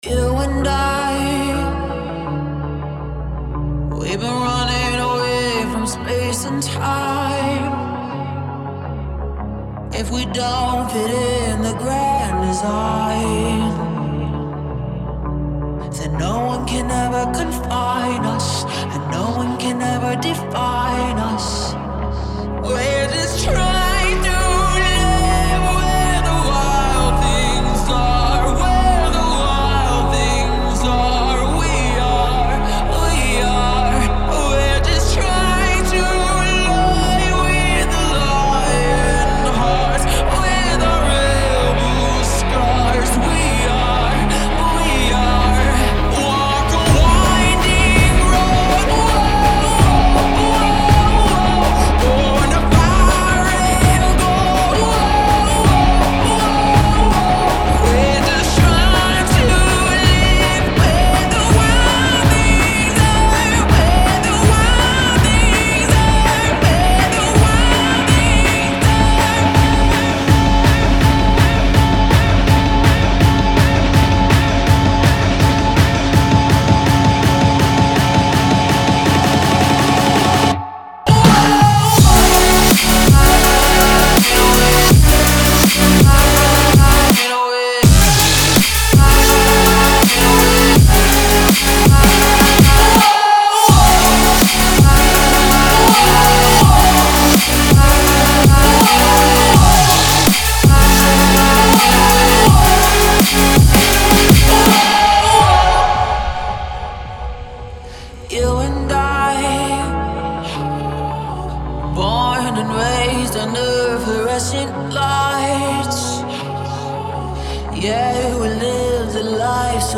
это захватывающий трек в жанре EDM